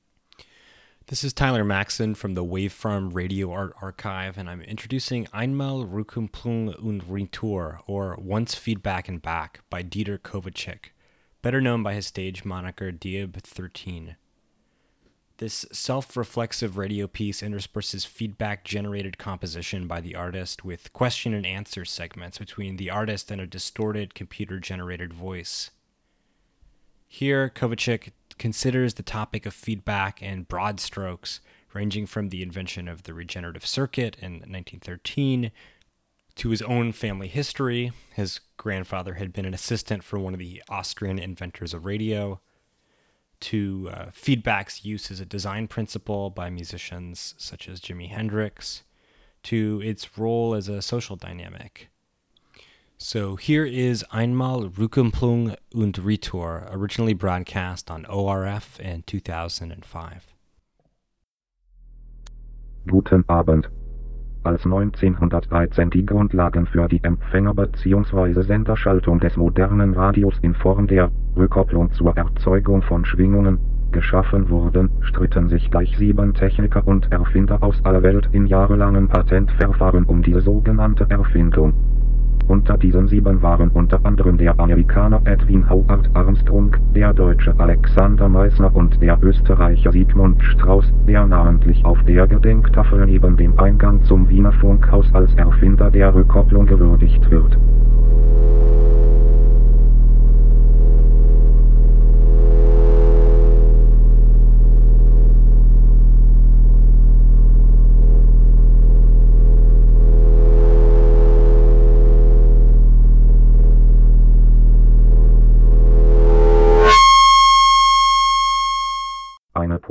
a reflexive radio piece
a distorted, computer-generated voice